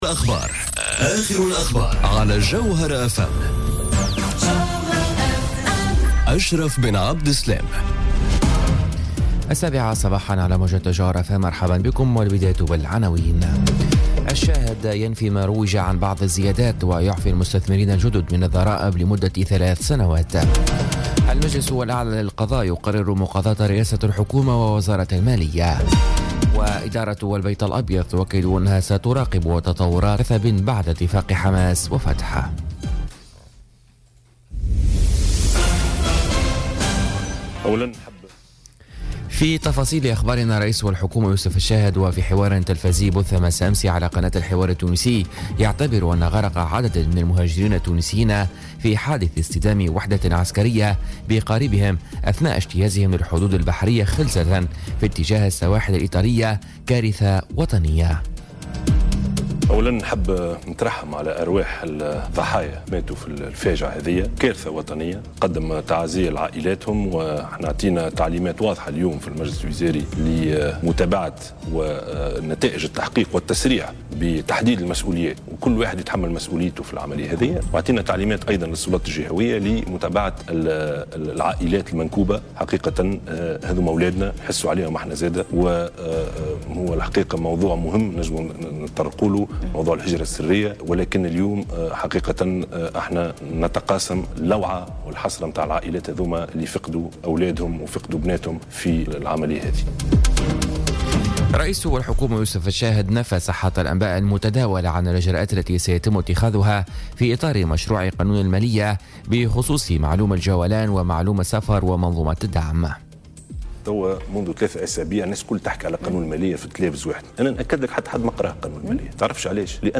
نشرة أخبار السابعة صباحا ليوم الجمعة 13 أكتوبر 2017